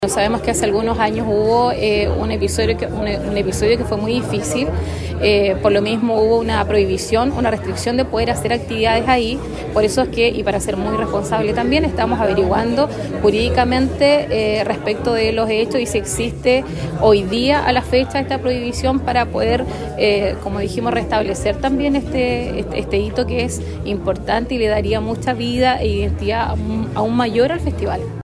En conferencia de prensa realizada en el Hotel Araucano, en el centro penquista, se dieron a conocer los principales hitos de esta edición especial, marcada por el fortalecimiento de la industria audiovisual regional y nacional, con el apoyo tanto del sector público como de la empresa privada.